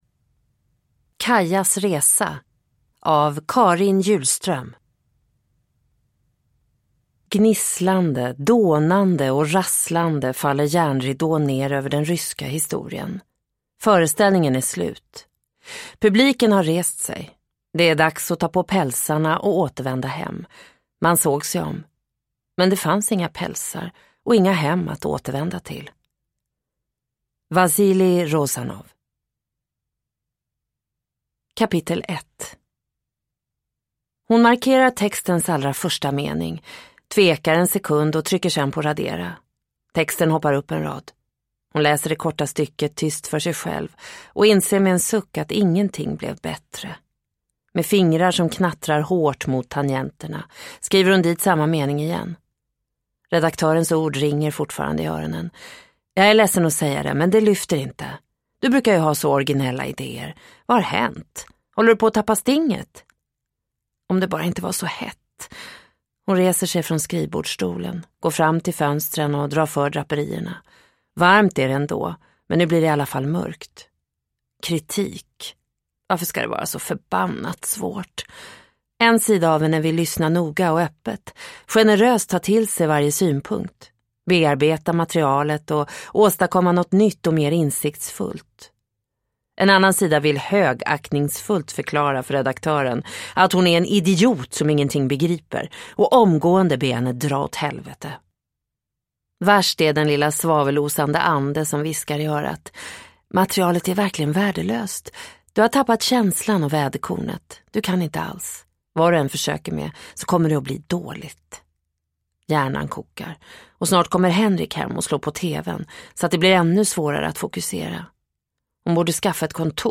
Kajas resa – Ljudbok – Laddas ner